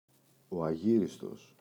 αγύριστος, ο [aꞋʝiristos]